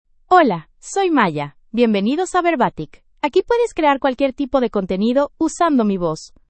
FemaleSpanish (United States)
MayaFemale Spanish AI voice
Maya is a female AI voice for Spanish (United States).
Voice sample
Listen to Maya's female Spanish voice.
Maya delivers clear pronunciation with authentic United States Spanish intonation, making your content sound professionally produced.